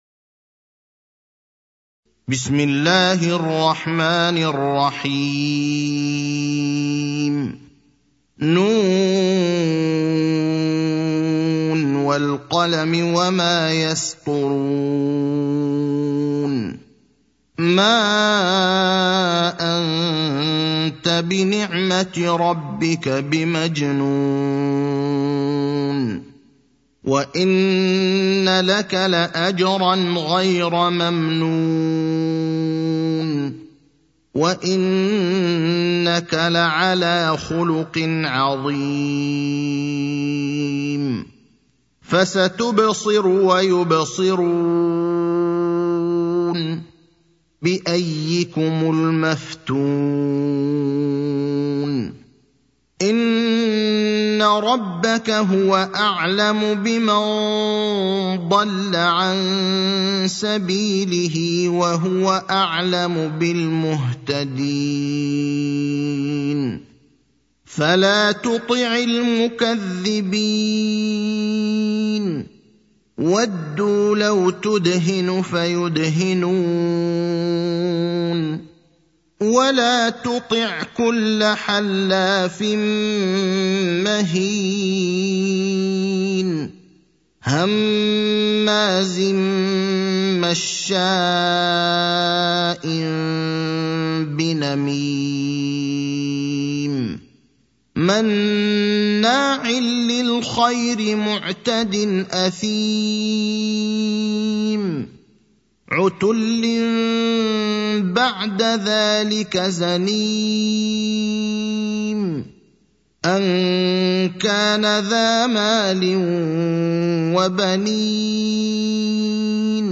المكان: المسجد النبوي الشيخ: فضيلة الشيخ إبراهيم الأخضر فضيلة الشيخ إبراهيم الأخضر القلم (68)